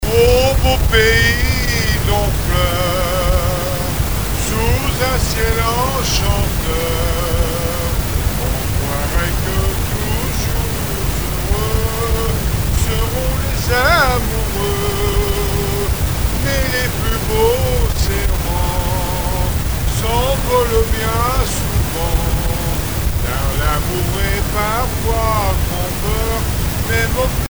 Chansons traditionnelles et témoignages
Pièce musicale inédite